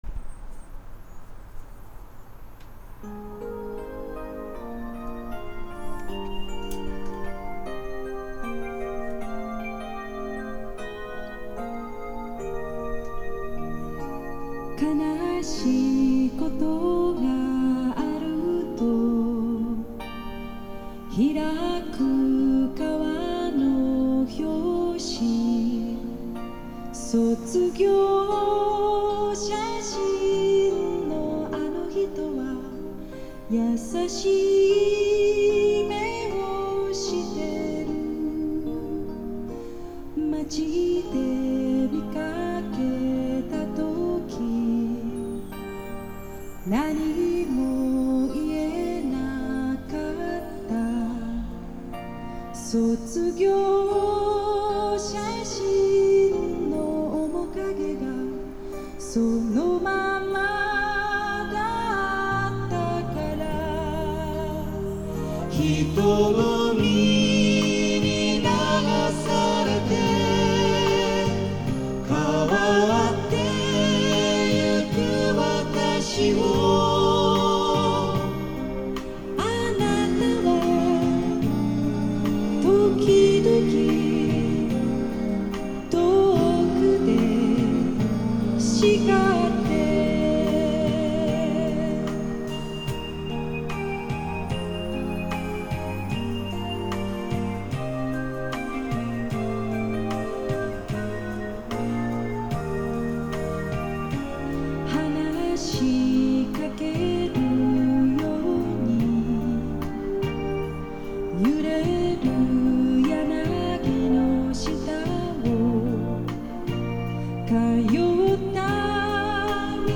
Pioneer PE-101A：１０ｃｍフルレンジスピーカー
ライブアルバムのCDを再生しました。